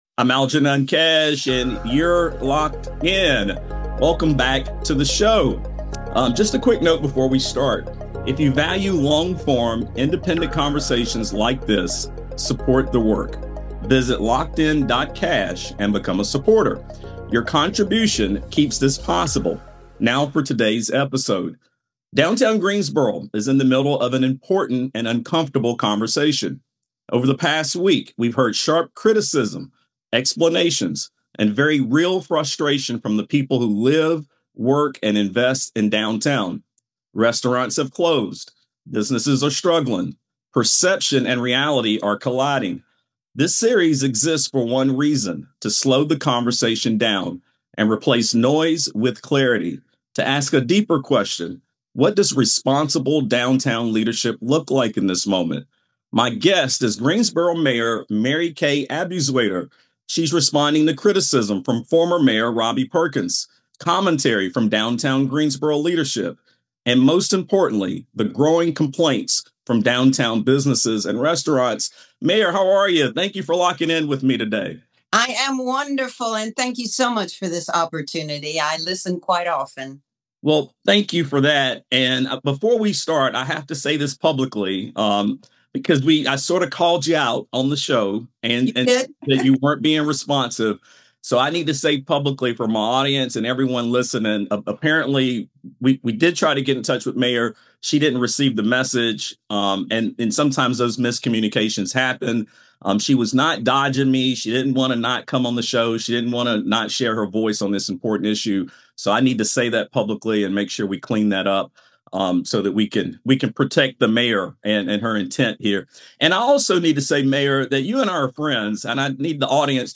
In this episode, Greensboro Mayor Marikay Abuzuaiter joins me for a candid governing conversation about the real issues shaping downtown today: public safety, parking, homelessness, the role of Downtown Greensboro Inc., financing redevelopment, and the balance between perception and lived experience.